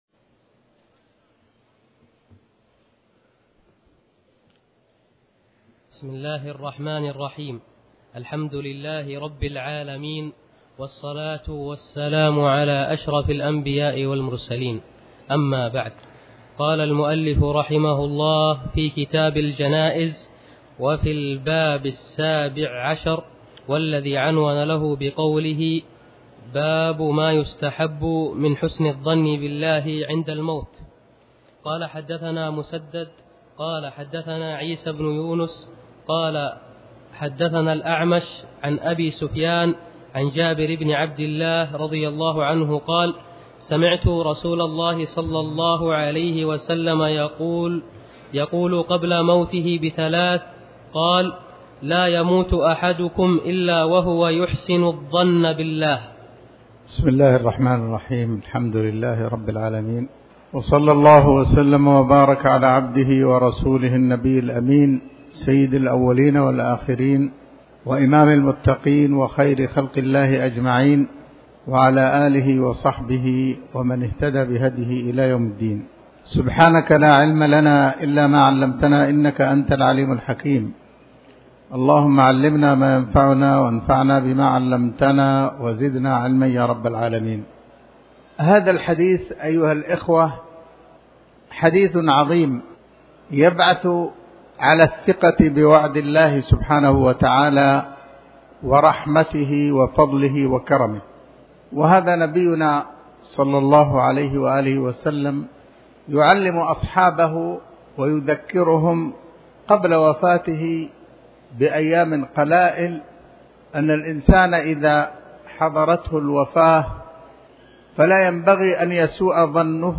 تاريخ النشر ١٩ محرم ١٤٤٠ هـ المكان: المسجد الحرام الشيخ